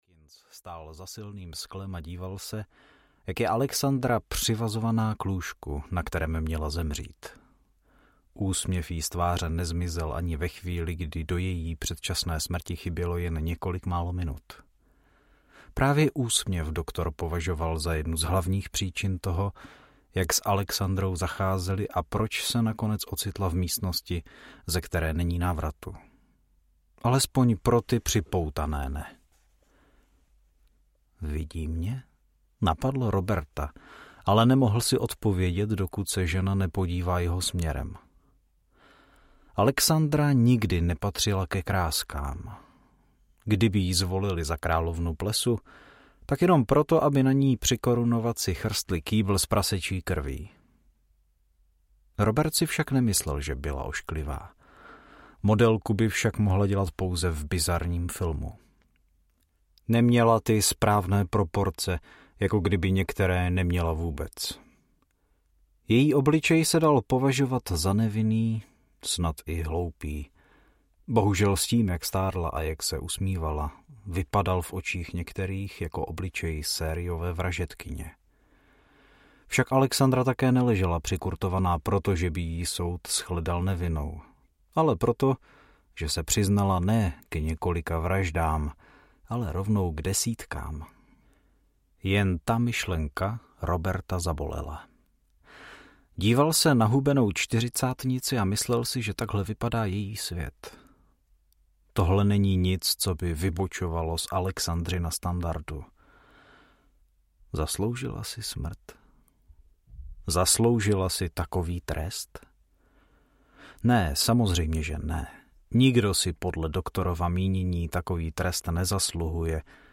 Nikdy se nepřestala usmívat audiokniha
Ukázka z knihy